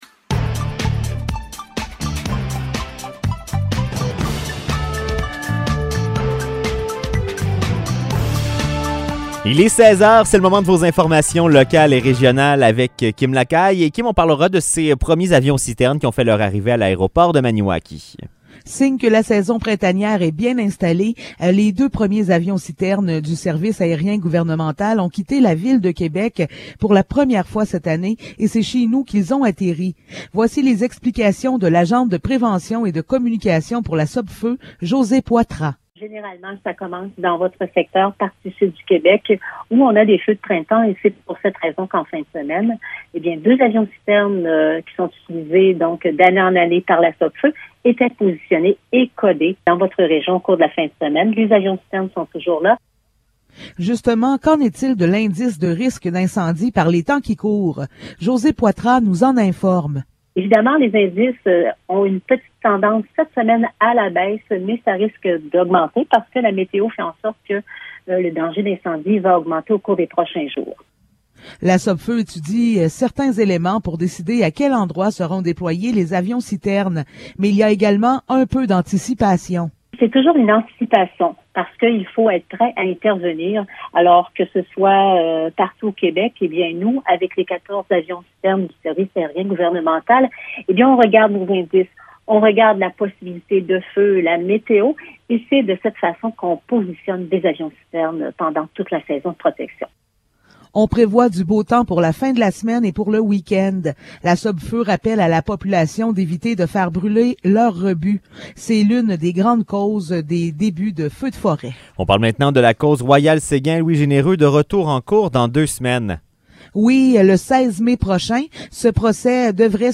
Nouvelles locales - 2 mai 2022 - 16 h